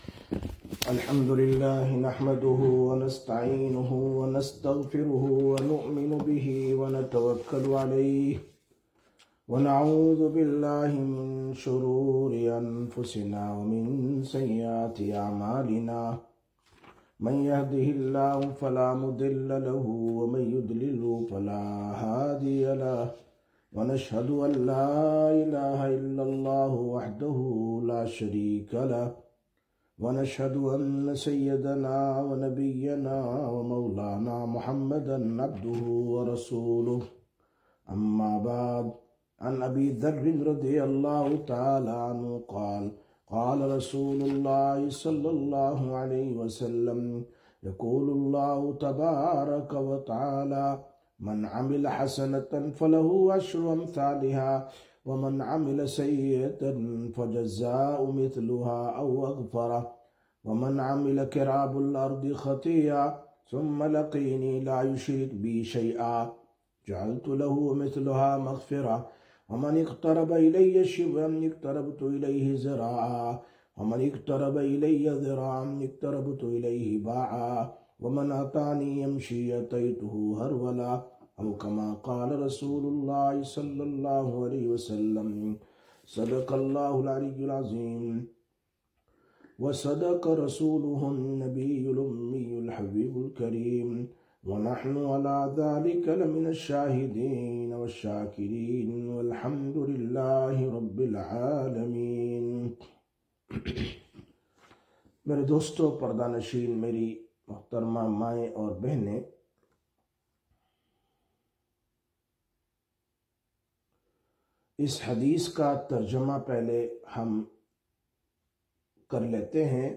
14/05/2025 Sisters Bayan, Masjid Quba